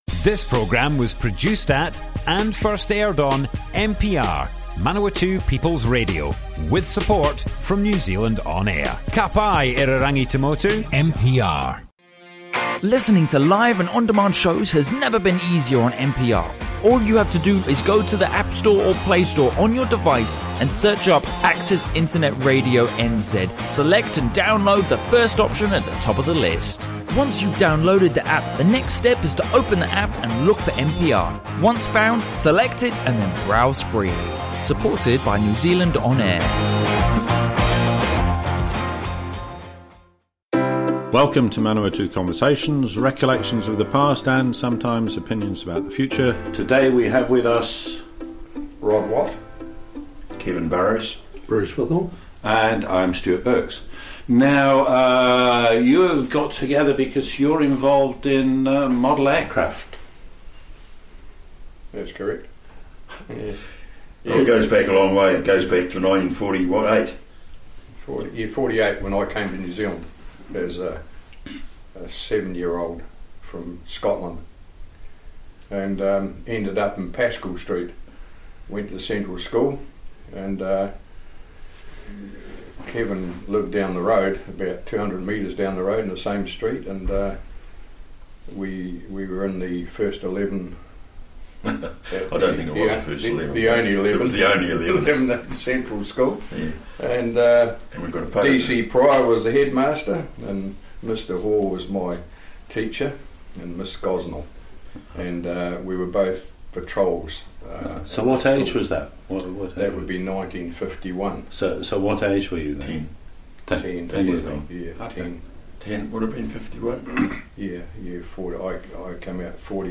Manawatū Conversations More Info → Description Broadcast on Manawatū People's Radio 30 July, 2019.
oral history